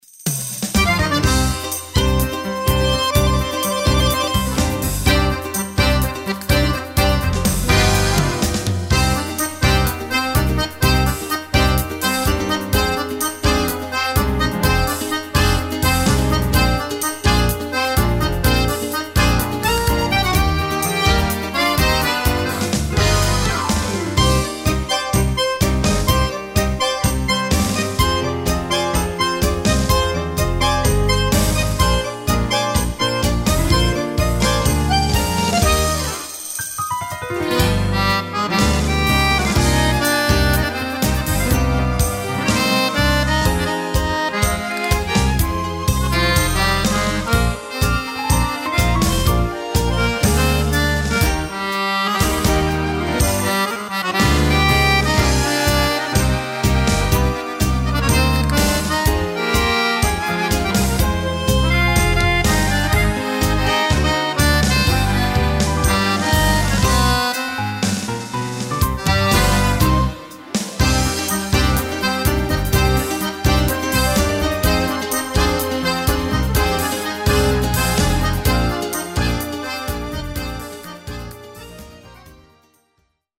New tango
Fisarmonica